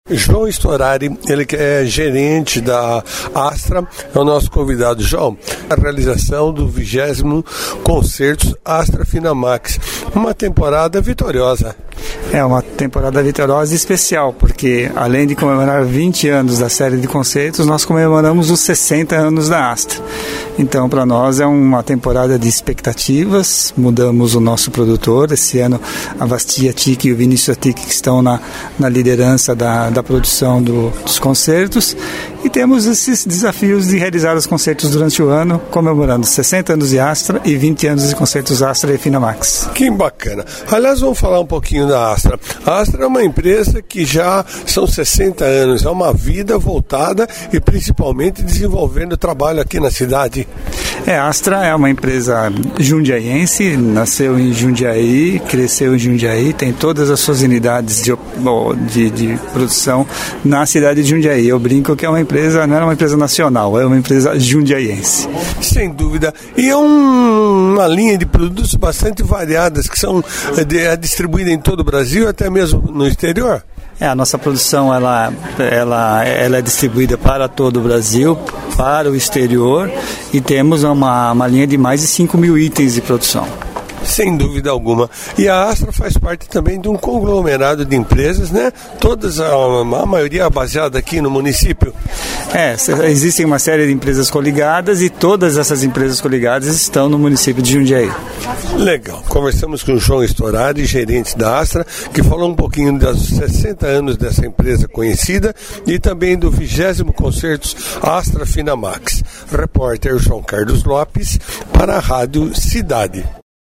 EntrevistasNOTÍCIAS